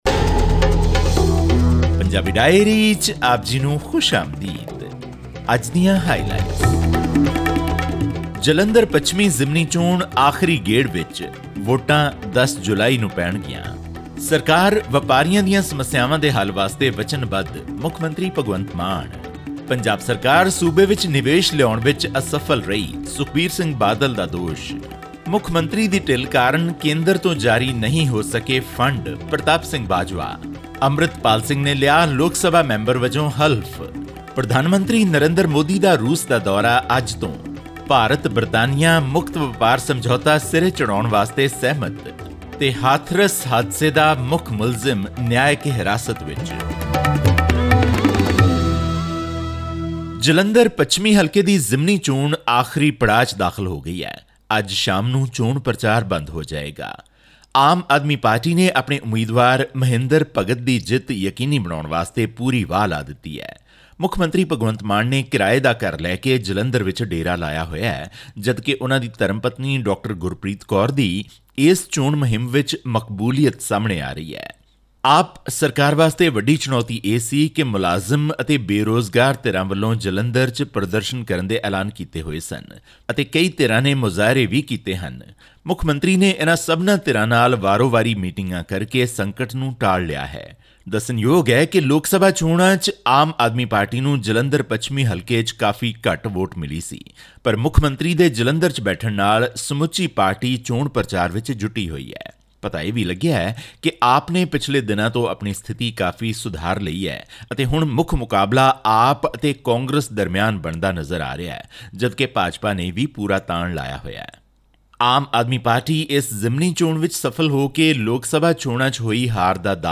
ਇਸ ਸਬੰਧੀ ਹੋਰ ਵੇਰਵੇ, ਅਤੇ ਪੰਜਾਬ ਦੀਆਂ ਹੋਰ ਖਬਰਾਂ ਲਈ ਸੁਣੋ ਇਹ ਰਿਪੋਰਟ...